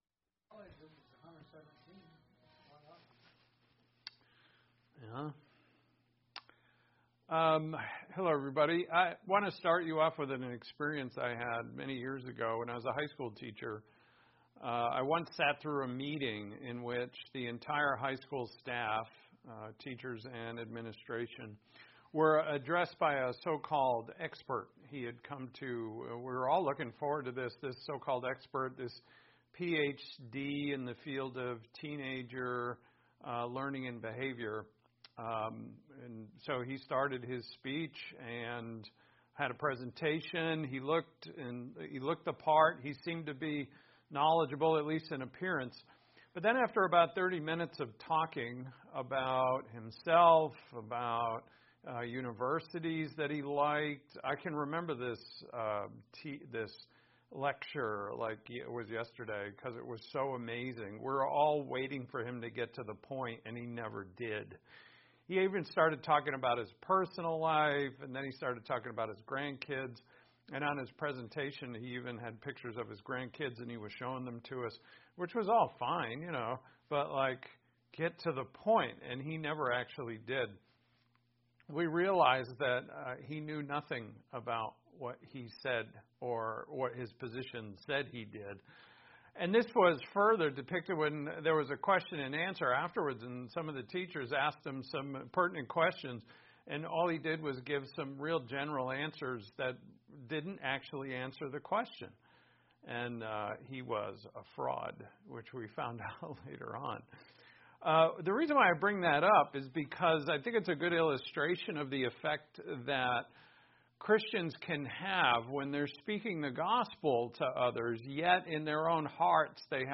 Main idea: The fuel that propels the gospel into the hearts of others is unity in the church. Introduction to the next Sermon in Matthew.